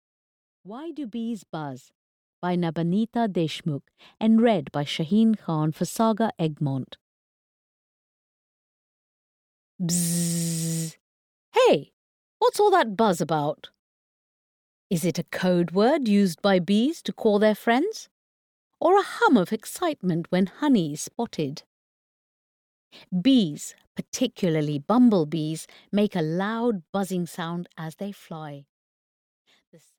Why do Bees Buzz? (EN) audiokniha
Ukázka z knihy